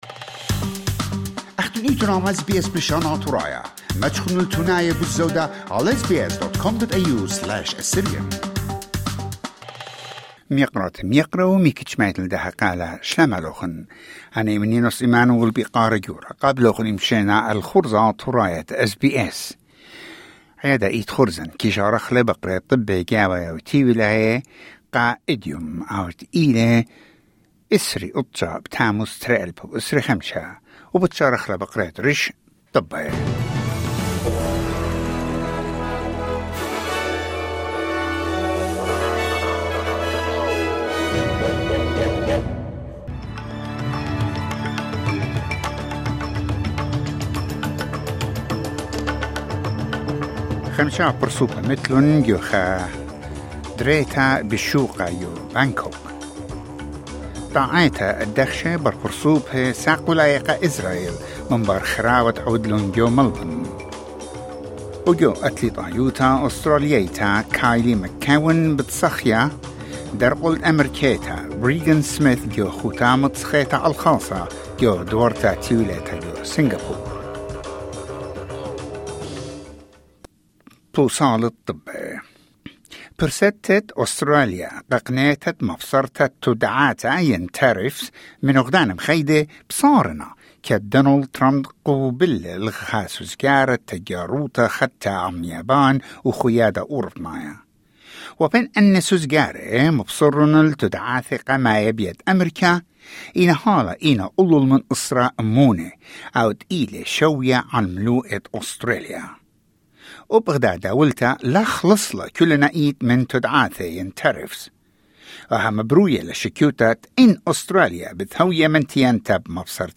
SBS News in Assyrian: 29 July 2025